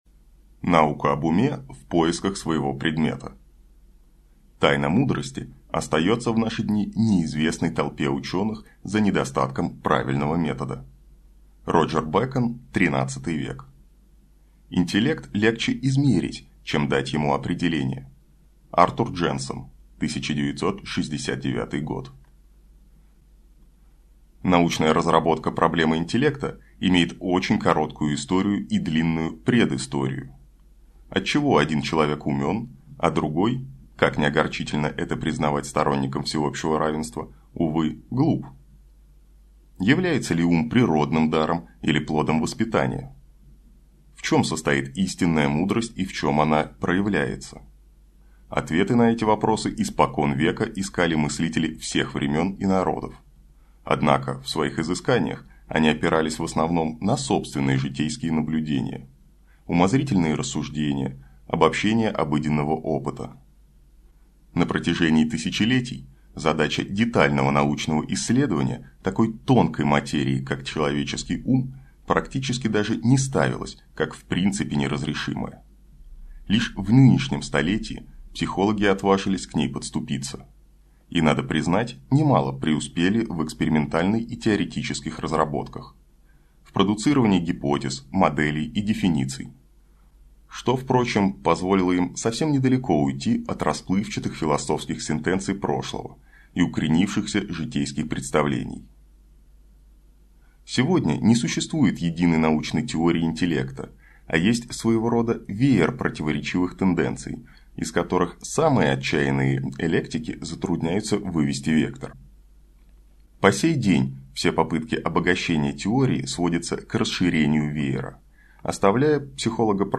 Аудиокнига Приключения IQ, или Кто на свете всех умнее | Библиотека аудиокниг